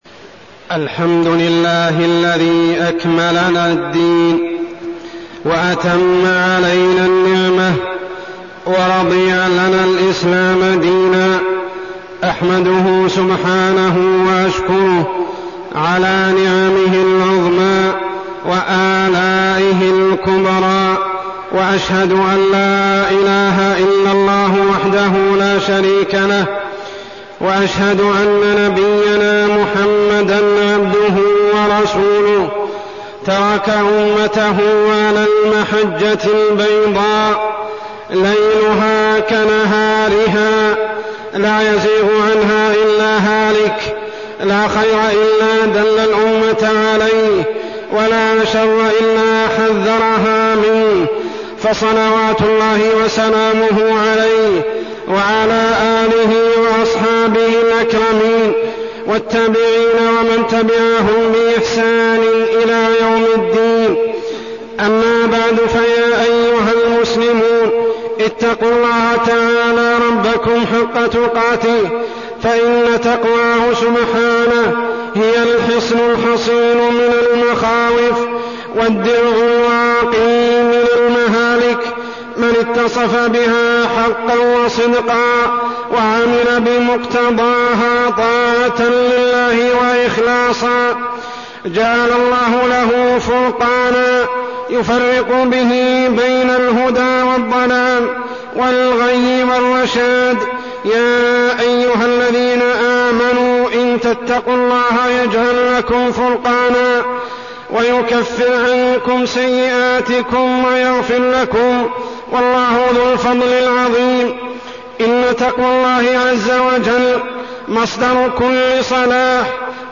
تاريخ النشر ٢٣ ذو الحجة ١٤١٦ هـ المكان: المسجد الحرام الشيخ: عمر السبيل عمر السبيل تحقيق التقوى